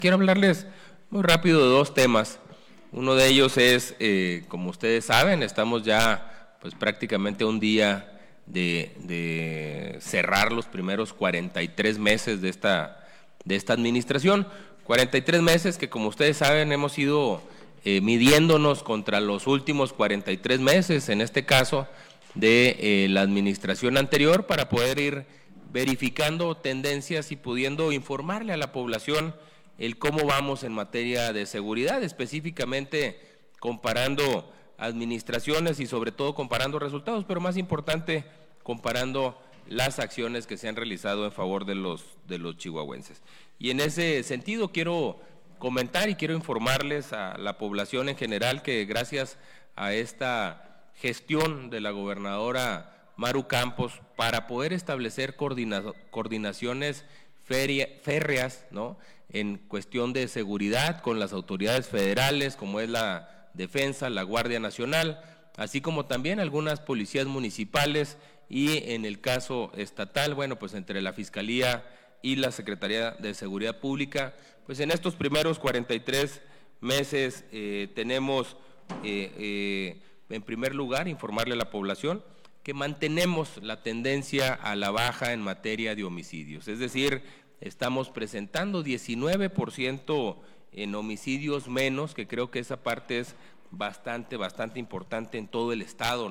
Entre los índices delictivos del mismo período, que Loya Chávez mencionó durante una rueda de prensa, se encuentran una reducción del 58% en la extorsión, del 33% en el robo a casa habitación con violencia y del 9% en delitos contra el medio ambiente, derivada esta última a partir de la estrategia para eliminar la tala ilegal en la Sierra Tarahumara.